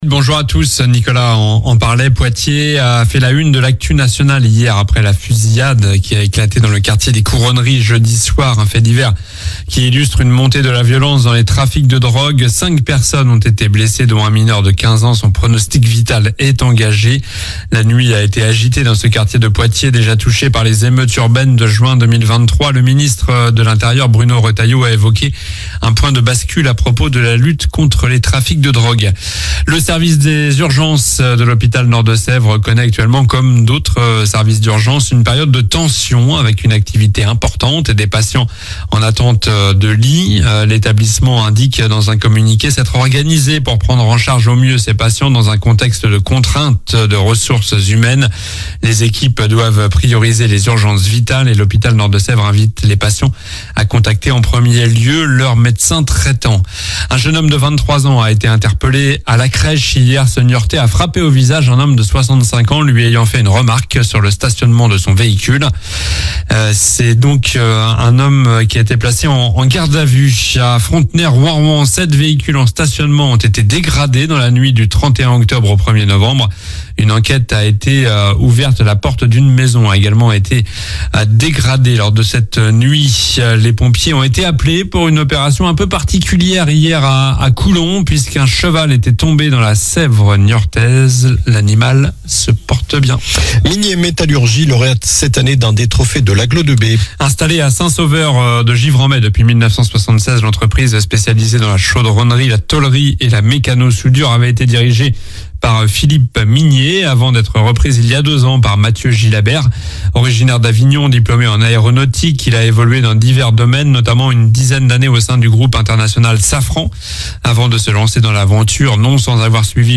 Journal du samedi 02 novembre (matin)